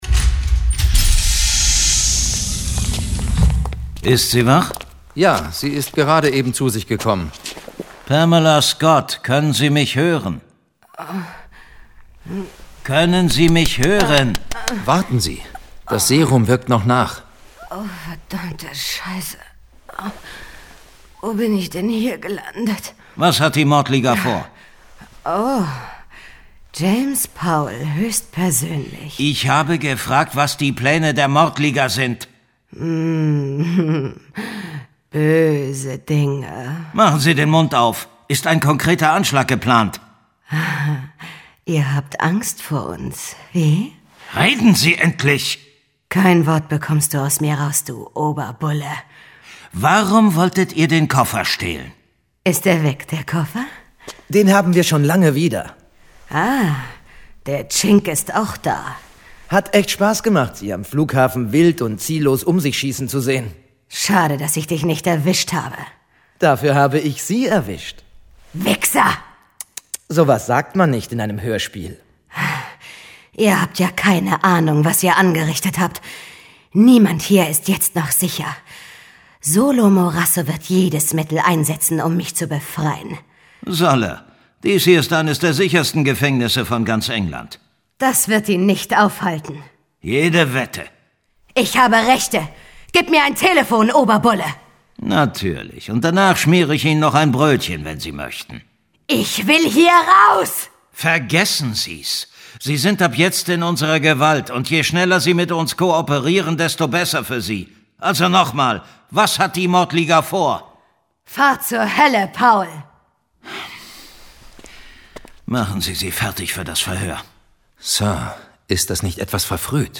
John Sinclair - Folge 44 Das Elixier des Teufels. Hörspiel.